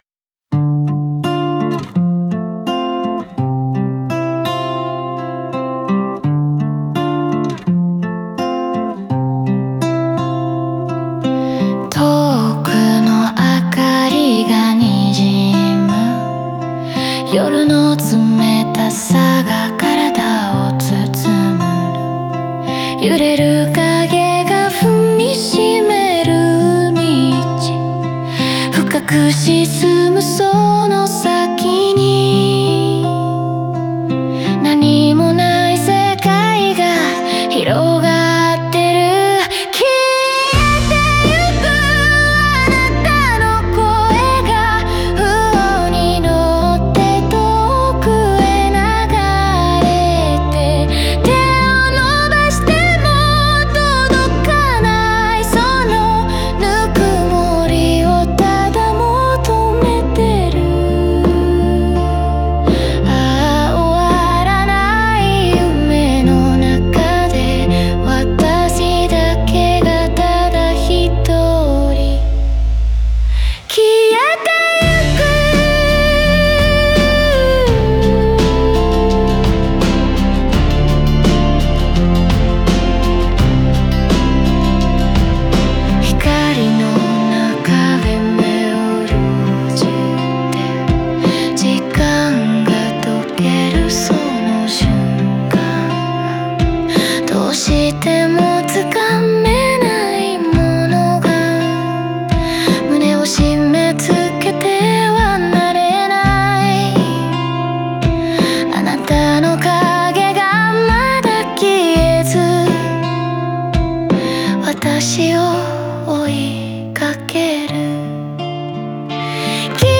曲の展開に合わせて、感情の高まりと静けさが交錯し、幻想的で切ない雰囲気を作り上げています。